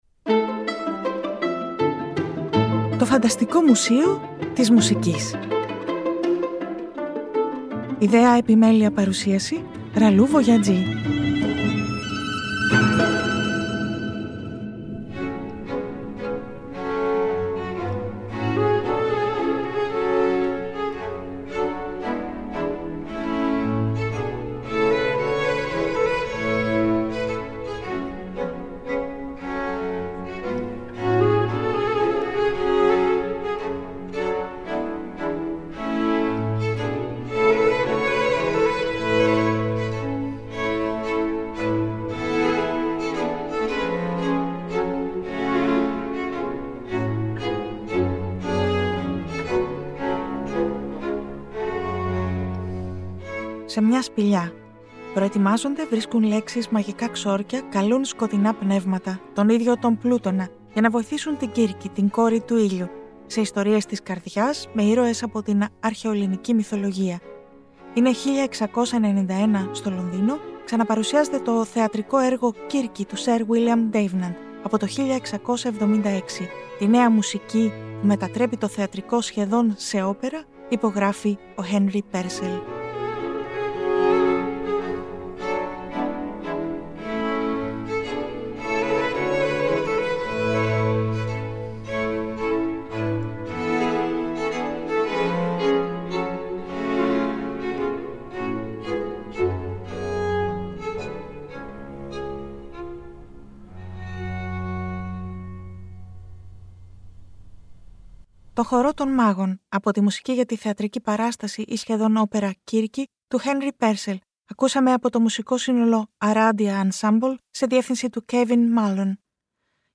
Το 2024 Το ΦΑΝΤΑΣΤΙΚΟ ΜΟΥΣΕΙΟ της ΜΟΥΣΙΚΗΣ (Τhe FANTASTIC MUSEUM of MUSIC) είναι μια ραδιοφωνική εκπομπή , ένας «τόπος» φαντασίας στην πραγματικότητα .